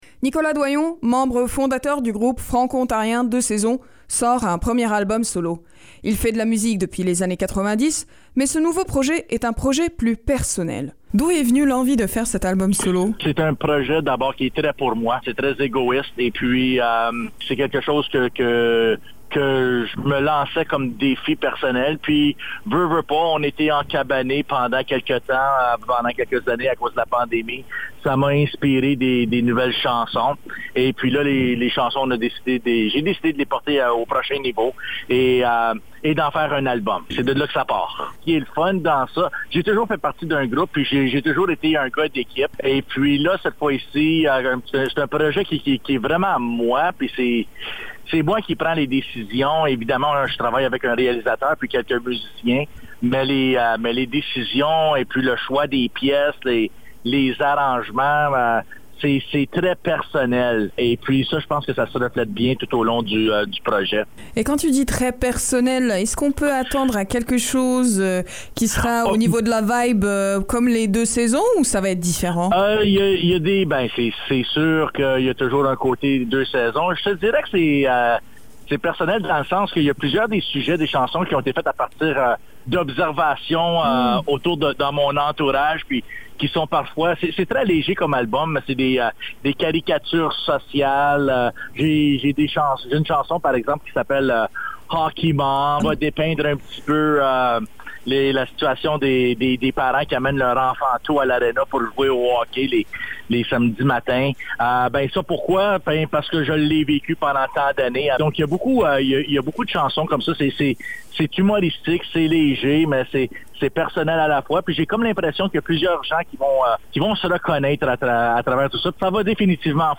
L'entrevue